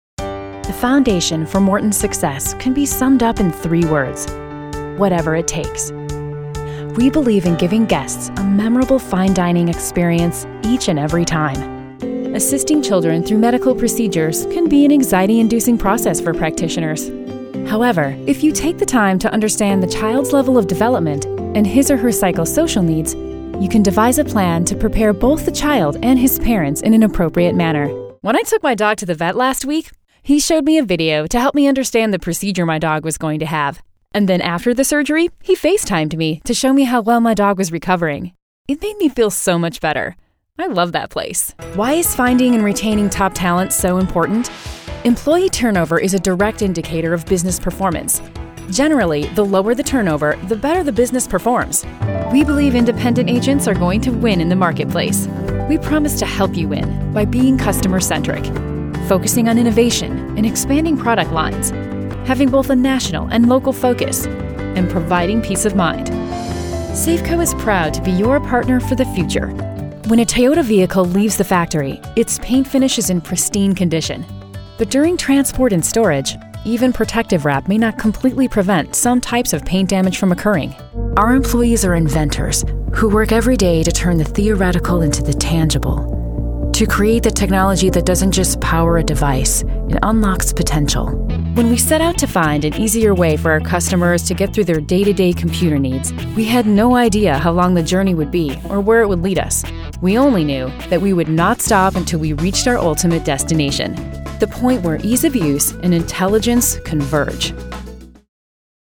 Authoritative, down to earth, smart, warm, and witty with a dash of sarcasm and sass
Corporate Narration
English (North American)
My studio is equipped with a WhisperRoom, Neumann TLM 103, Focusrite 2i2 Preamp and ProTools.